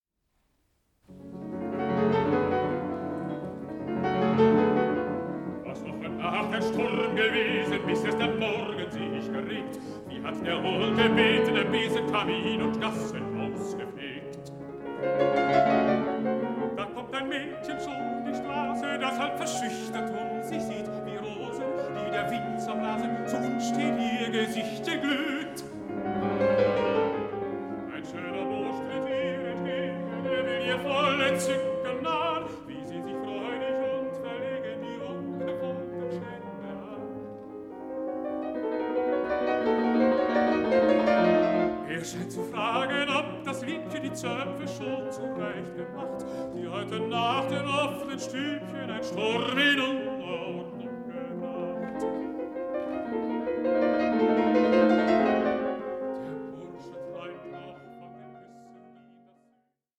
Songs to poems